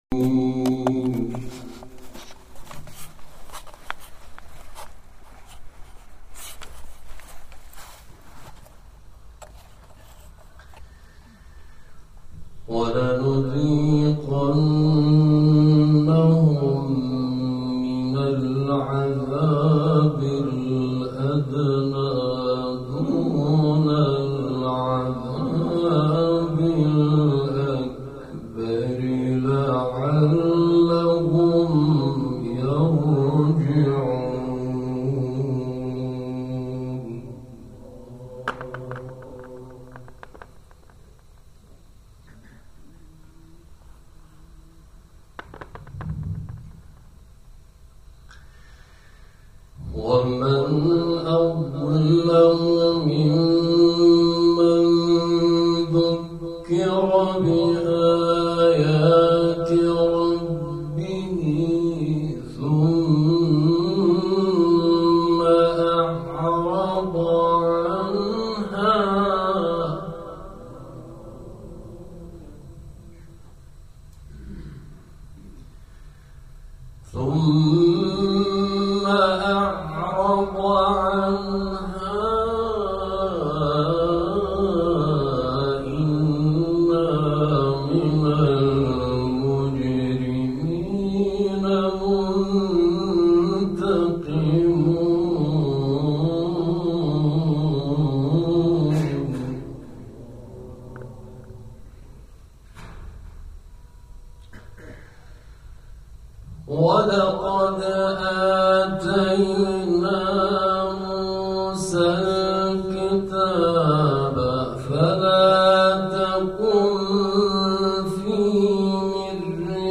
سپس، به تحلیل تلاوت خود پرداخت و گفت: در این تلاوت، متناسب با معنای آیات، از دو دستگاه بیات و صبا استفاده شد.